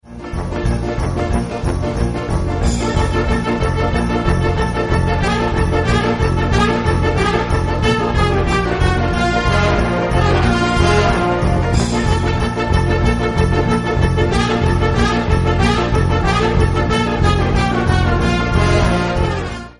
Gattung: Ballett in 4 Akten
Besetzung: Blasorchester